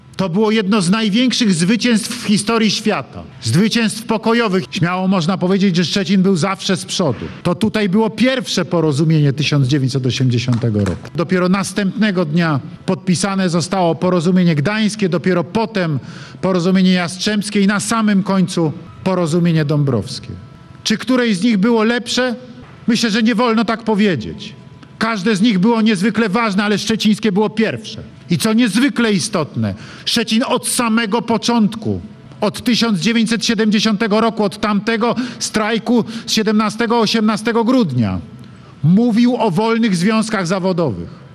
Dziś mijają 43 lata od czasu podpisania porozumień sierpniowych. Prezydent Andrzej Duda przed bramą stoczni szczecińskiej mówił, że to było jedno z największych zwycięstw, zwycięstwo, które zmieniło Polskę, świat i Europę.